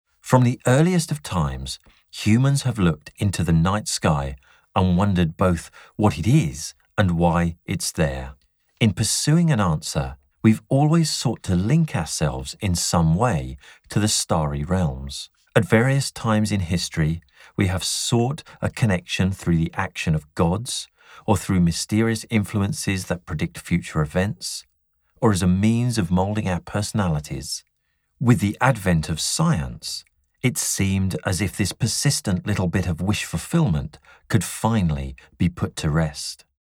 Audiobook Production
We will capture the audiobook narrators voice and ensure all is sounding correct ready for the publishing delivery.  The studio has a live room and isolation booth for either larger cast audiobook takes or just a single reader in the treated booth.